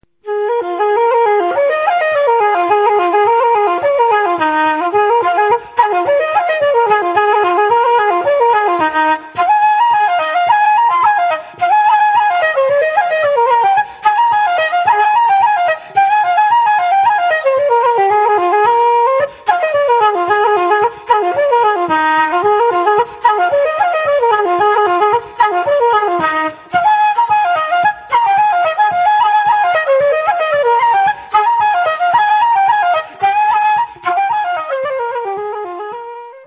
He has absorbed a great deal of the North of Ireland style and repertoire of flute-playing, but clearly this is not enough for him and he sets out to demonstrate the range and capacity of the flute in Irish traditional music in this very carefully programmed, full-length (72 minutes) album.
However, it is when he is playing reels flat-out that he is at his most exciting, for his masterly technique is such that he is always in full control.